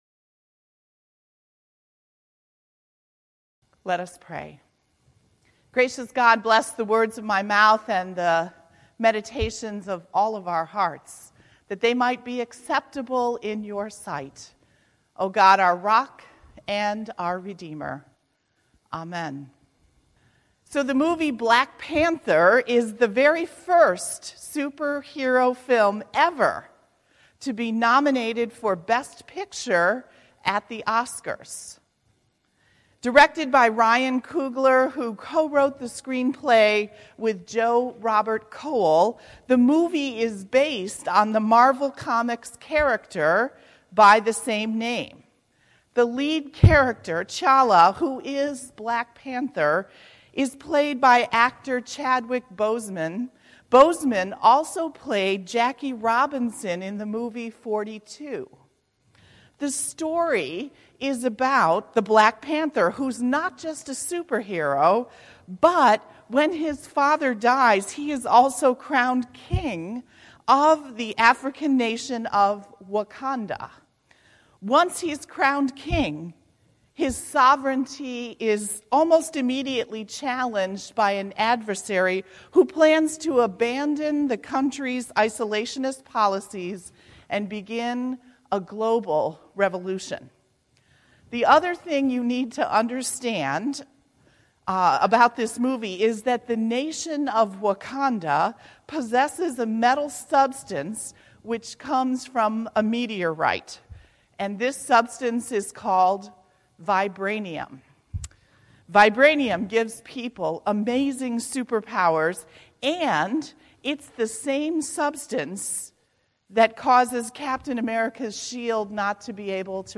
2019-02-17 Sermon, “Building Bridges”